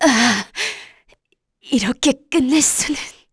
Glenwys-Vox_Dead_kr.wav